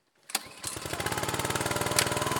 EngineStart.ogg